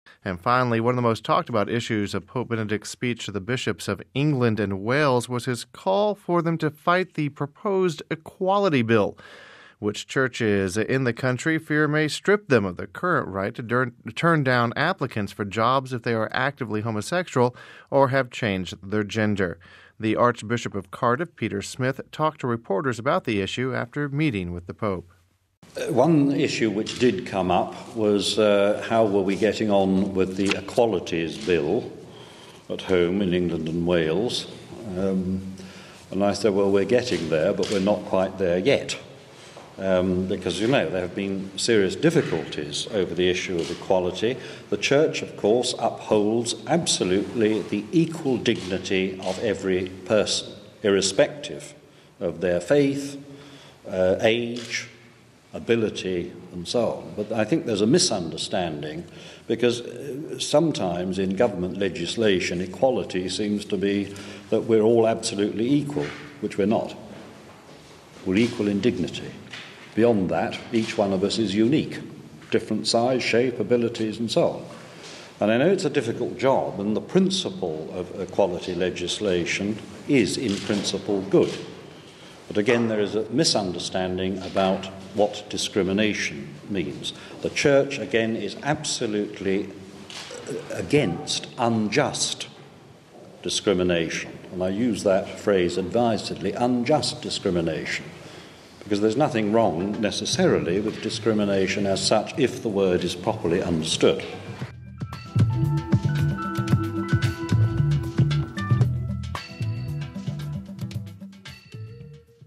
The Archbishop of Cardiff Peter Smith talked to reporters about the issue after meeting with the Pope...